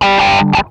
MANIC WAH 18.wav